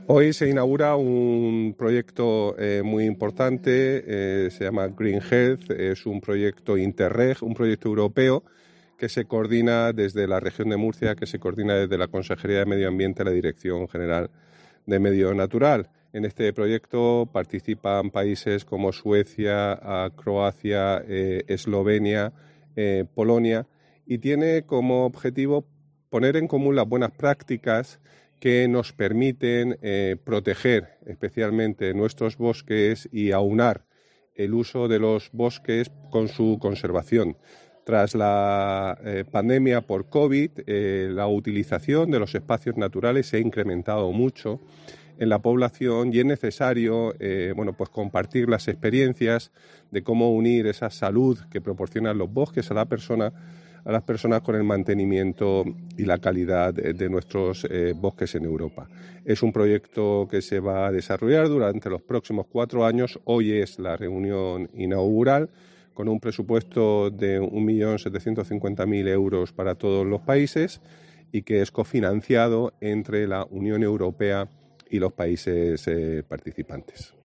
En una visita a agricultores de Blanca con motivo del Día de Europa, López Miras ha señalado que "la desalación no es un alternativa"